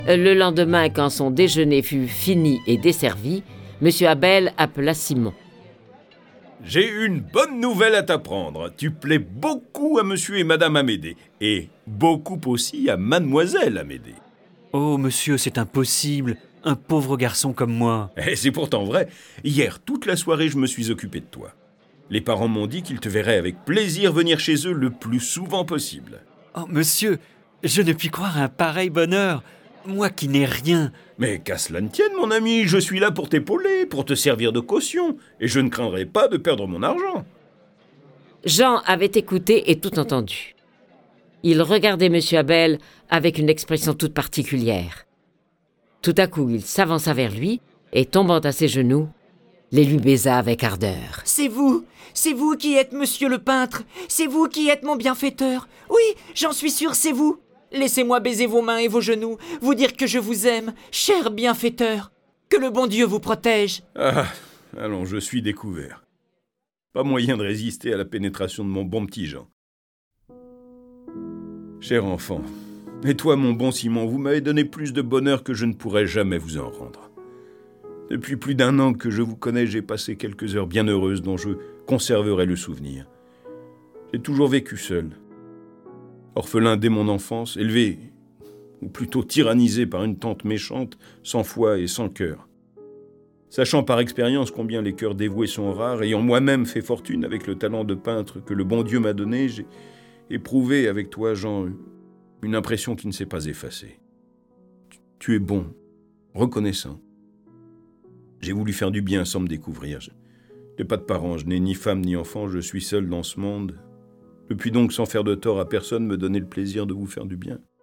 Cette version sonore des aventures de nos deux jeunes amis est animée par onze voix et accompagnée de près de trente morceaux de musique classique.